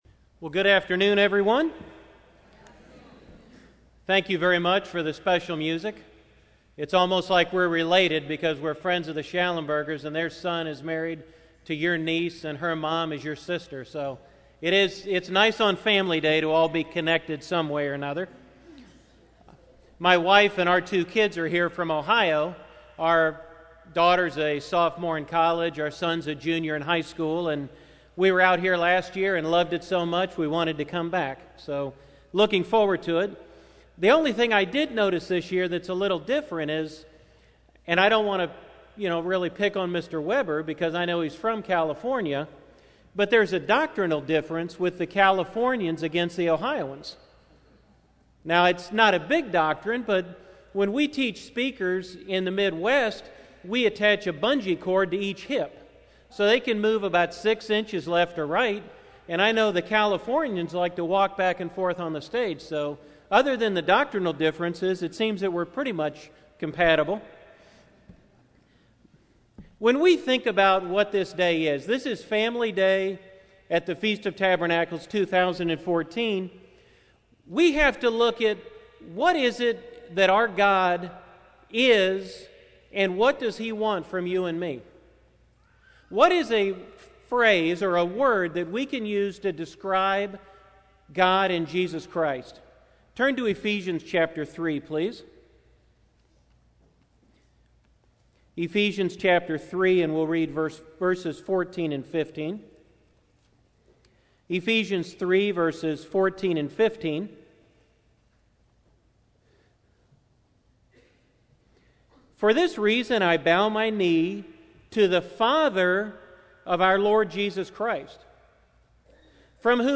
This sermon was given at the Oceanside, California 2014 Feast site.